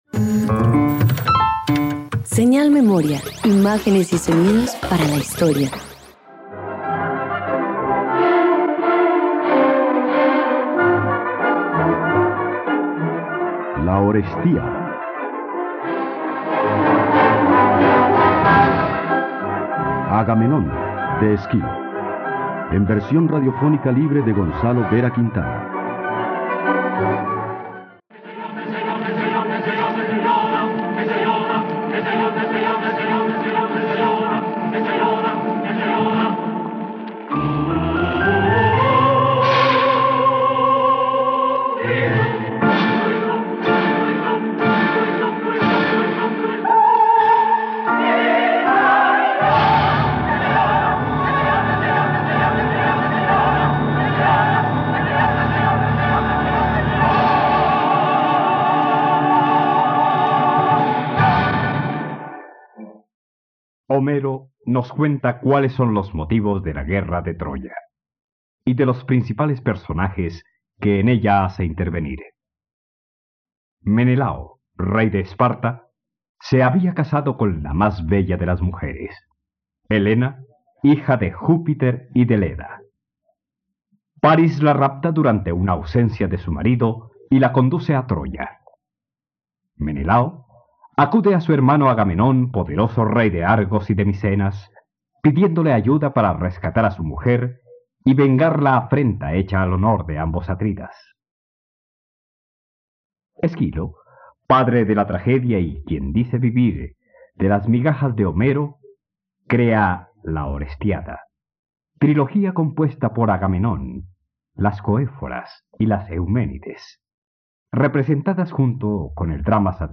..Radioteatro. Escucha la adaptación radiofónica de “Agamenón” del dramaturgo Esquilo por la plataforma de streaming RTVCPlay.